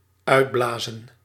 Ääntäminen
IPA: /ˈœy̯tˌblaːzə(n)/